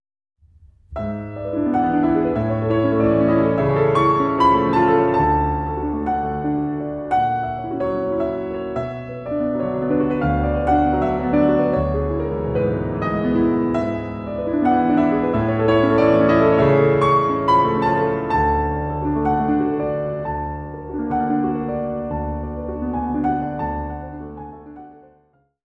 Augmented Triad
mp3Mendelssohn-Hensel, Fanny, Four Lieder for piano, Op. 2, No. 4, mm.1-7